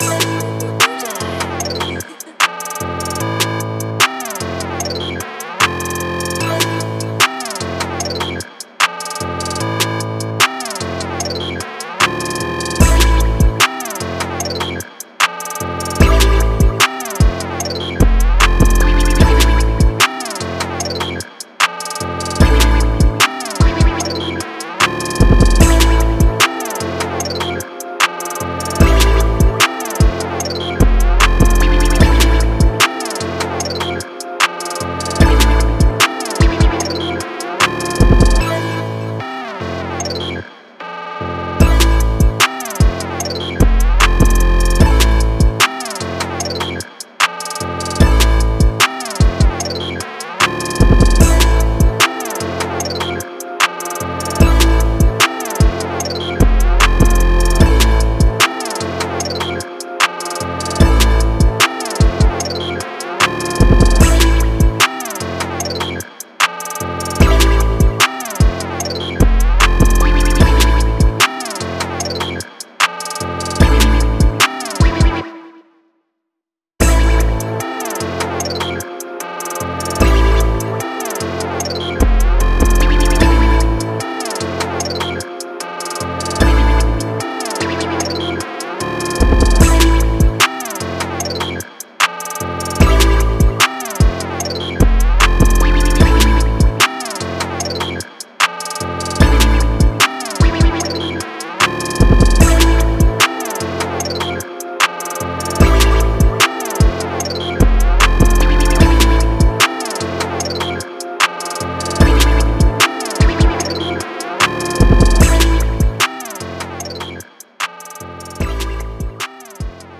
Key:Amin